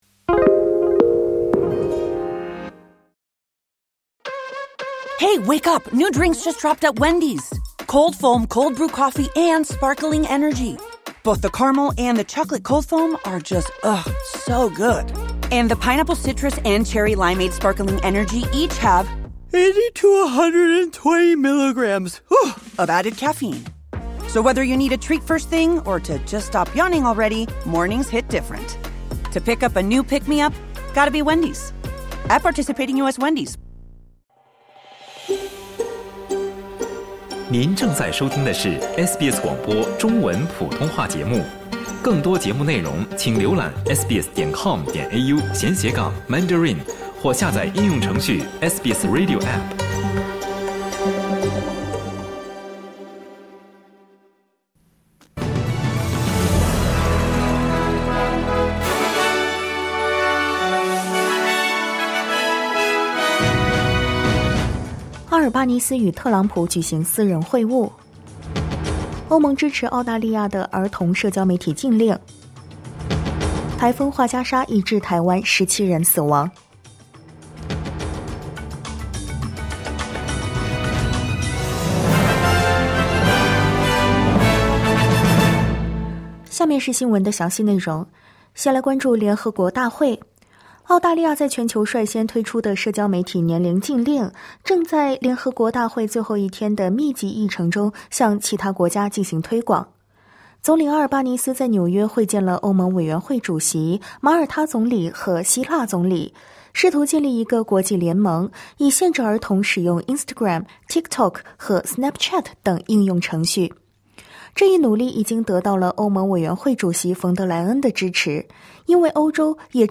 SBS早新闻（2025年9月25日）